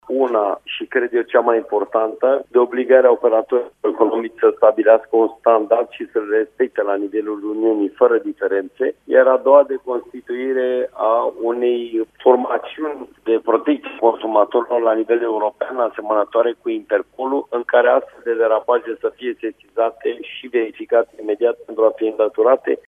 Unele din produse au mai multe grăsimi vegetale în Est, a declarat astăzi la Radio România Actualităţi, preşedintele ANPC, Horia Constantinescu.